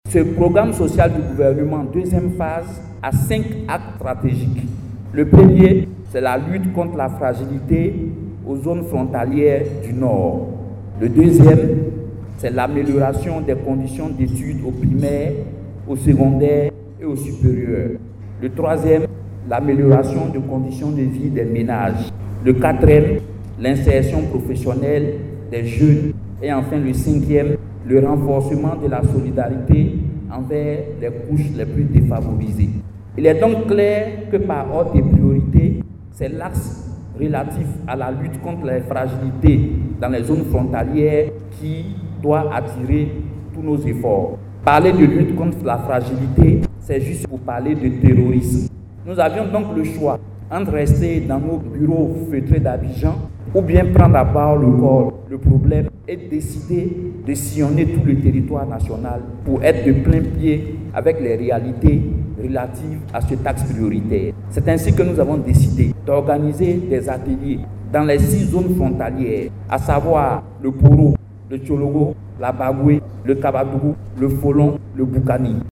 Financée à hauteur de 3.182,4 milliards F CFA, la deuxième phase du Programme Social du Gouvernement (PS-Gouv 2) qui a démarré en décembre 2021 pour prendre fin en 2024, portera sur cinq (5) axes stratégiques. Des priorités que décline ici, le Coordonnateur Général du Programme Social du Gouvernement.